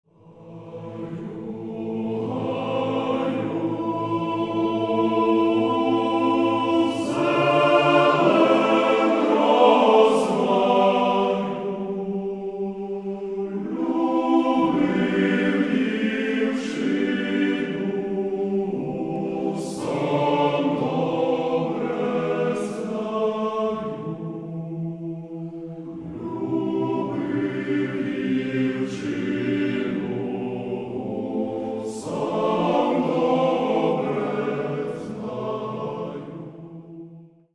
Каталог -> Народная -> Современные обработки
хор
сочетает акапельное и оркестрованное исполнение